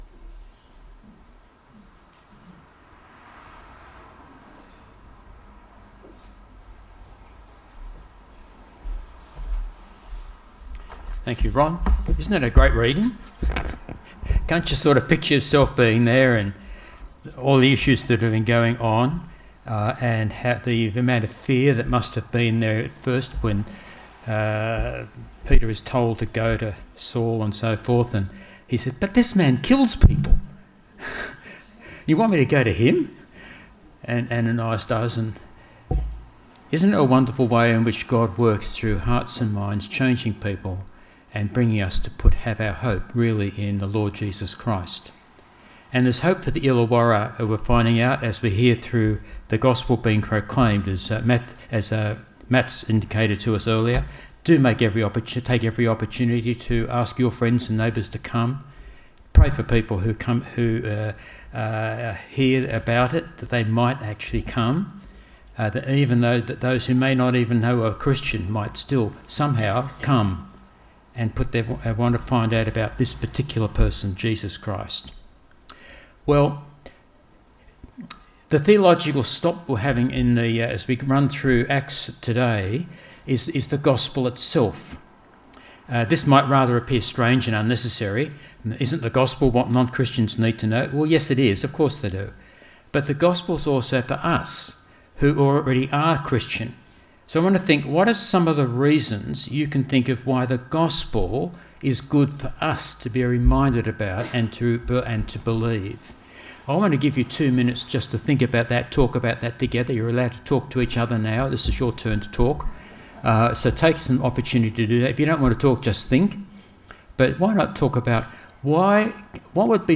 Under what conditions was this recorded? Christmas Day.